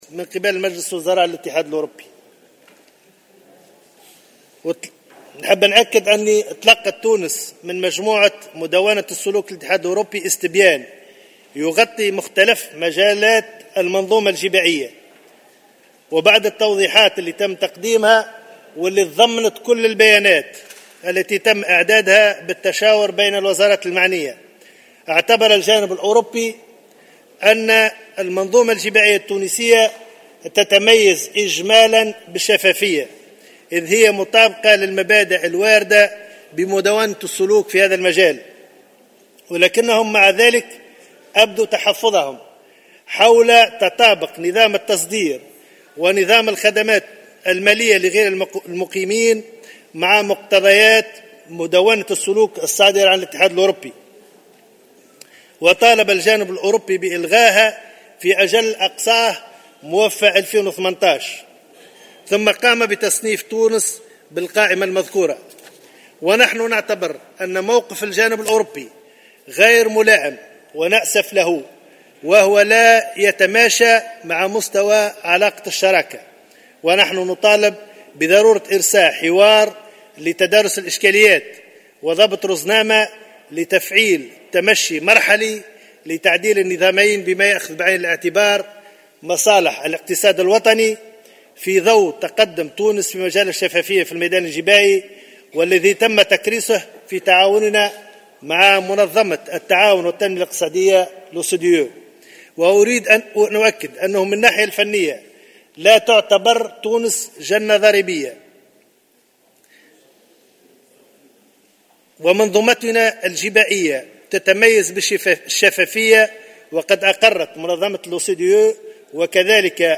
وكشف في معرض رده على أسئلة النواب في جلسة عامة حول قانون المالية 2018، أن تونس كانت تلقت من مجموعة مدوّنة السلوك بالاتحاد الأوروبي استبيانا يغطي مختلف مجالات المنظومة الجبائية.